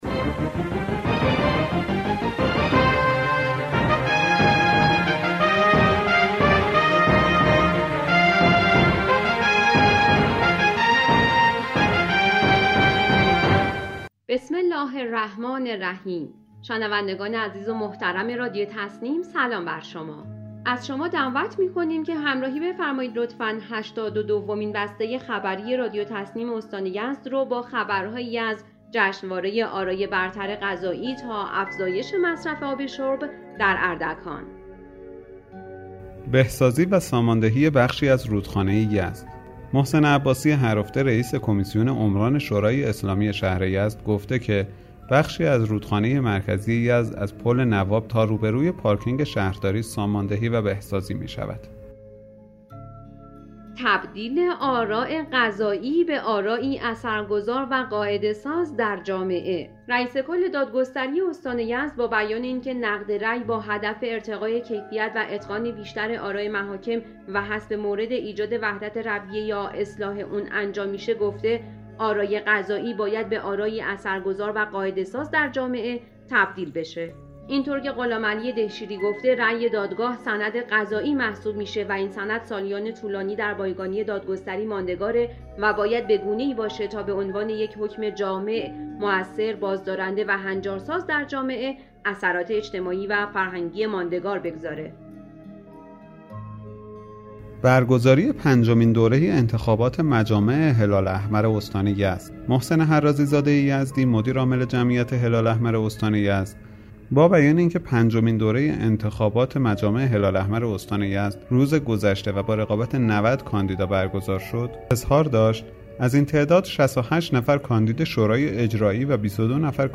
به گزارش خبرگزاری تسنیم از یزد, هشتاد و دومین بسته خبری رادیو تسنیم استان یزد با خبرهایی از توصیه رئیس کل دادگستری استان یزد در مورد آرا قضایی موثر در جامعه, توصیه آیت‌الله ناصری امام جمعه یزد به نمایندگان مجلس، روزهای بی رونقی در بازار کیف و کفش, کشف 70 راس دام غیرمجاز در یزد، بهسازی و ساماندهی مسیل یزد, برگزاری جشنواره برتر آرا قضایی استان و افزایش مصرف آب شرب در اردکان منتشر شد.